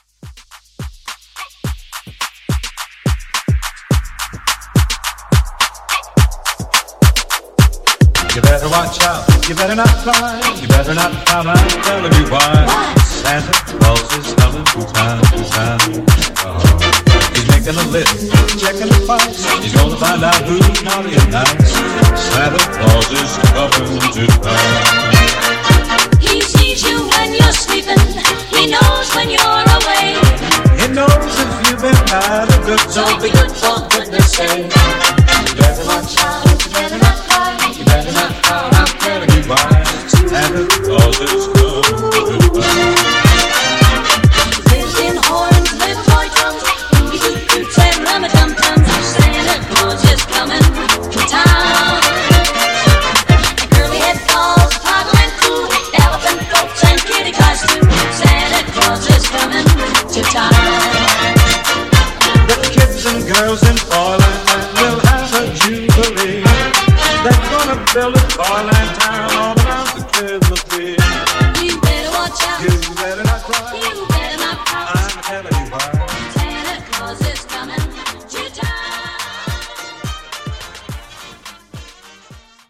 Genre: 90's
Clean BPM: 101 Time